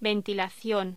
Locución: Ventilación
voz